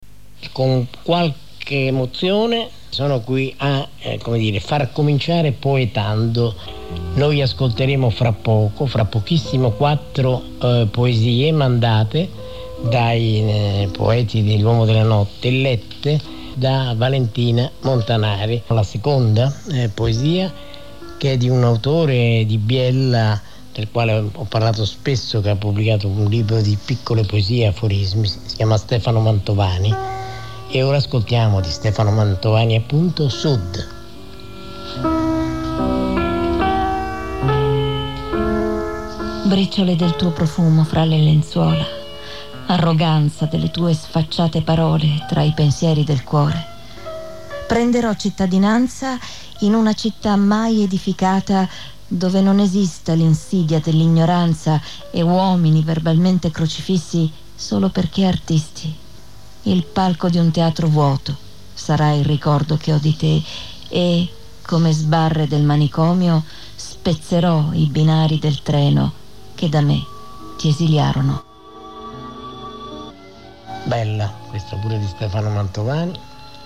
Poesia letta
rubrica dell’Uomo della notte,
condotto da Maurizio Costanzo